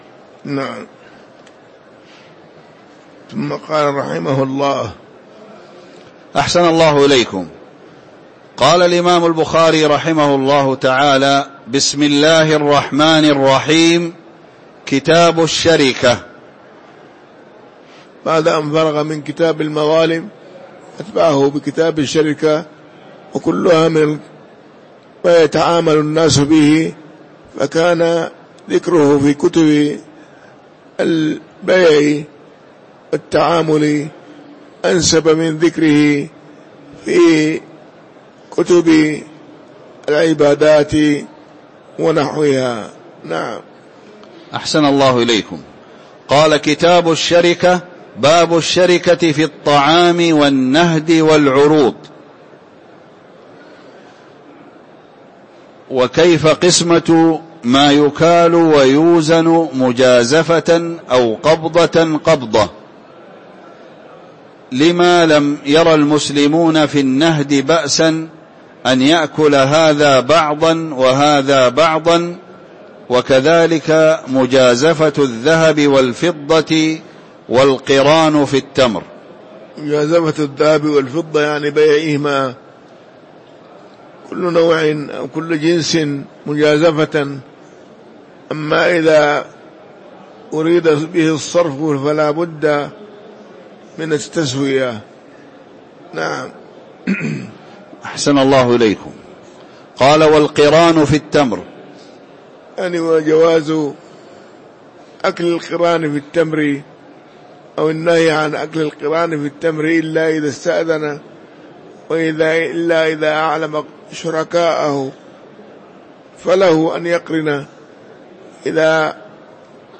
تاريخ النشر ١١ شعبان ١٤٤٥ هـ المكان: المسجد النبوي الشيخ